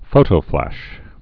(fōtō-flăsh)